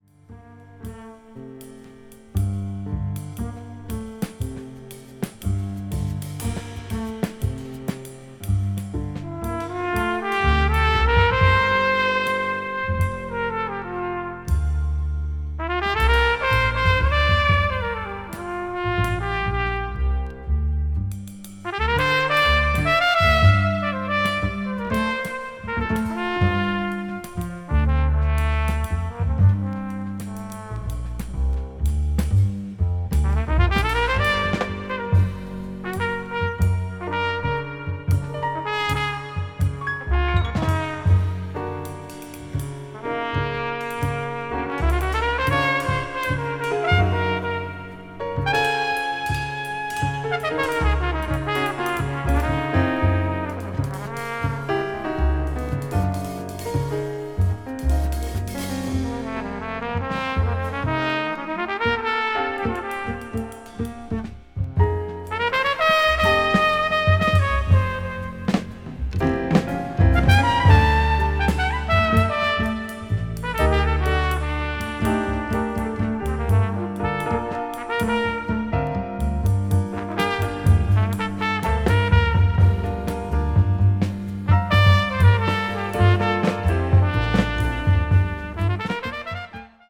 media : EX+/EX+(わずかにチリノイズが入る箇所あり)
contemporary jazz   deep jazz   ethnic jazz   spritual jazz